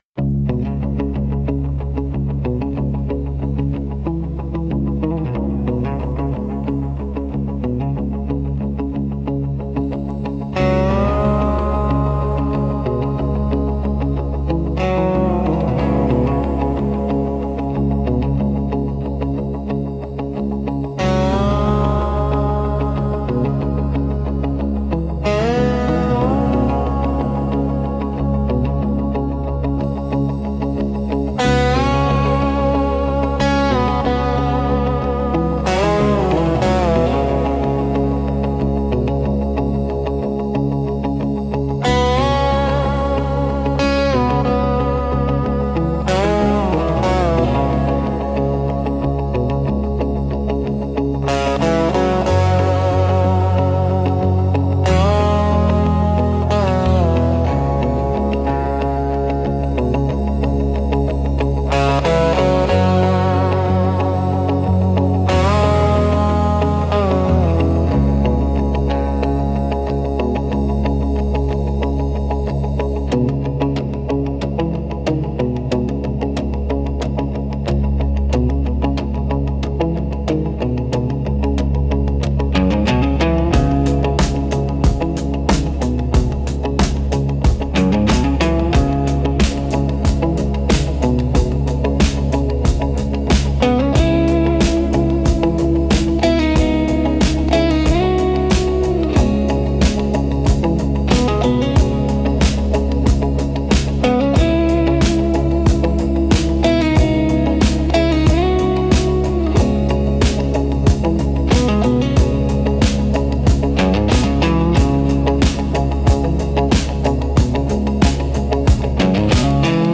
Instrumental - Forgotten Freeway Lament 4.22